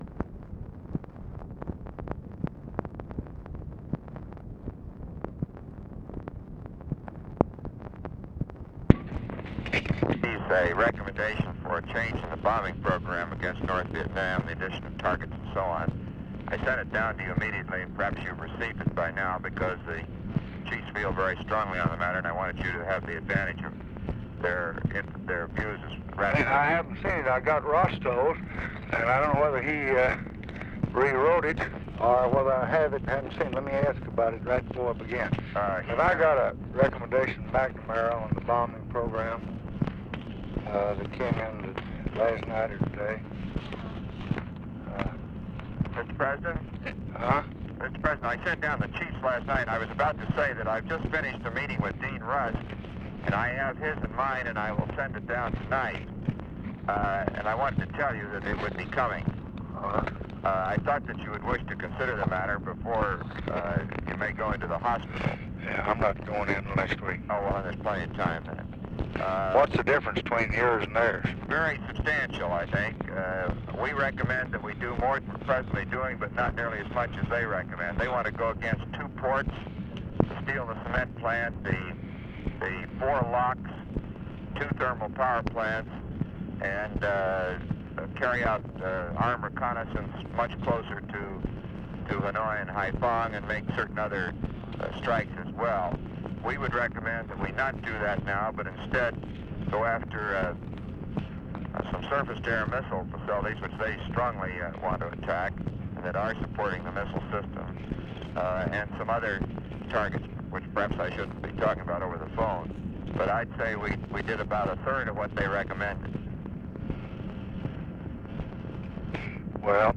Conversation with ROBERT MCNAMARA and OFFICE CONVERSATION, November 9, 1966
Secret White House Tapes